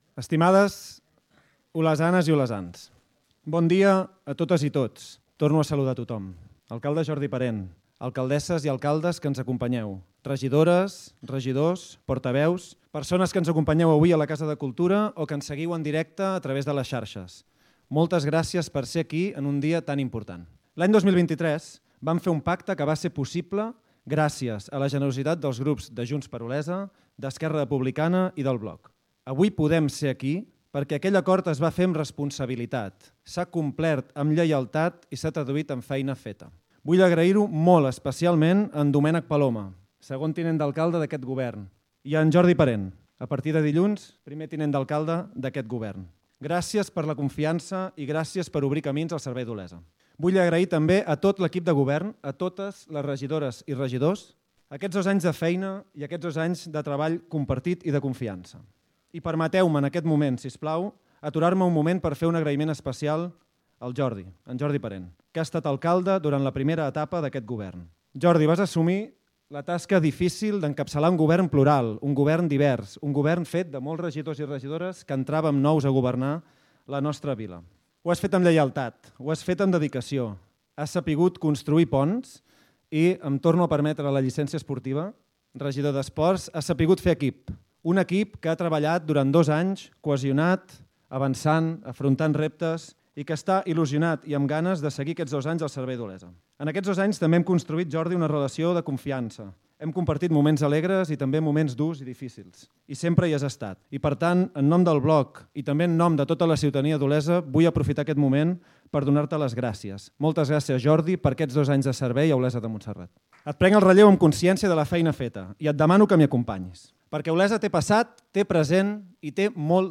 Discurs d'investidura de l'alcalde Marc Serradó
ple-investidura-serrado-parlament-investidura-marc-serrado.mp3